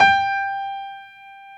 55p-pno27-G4.wav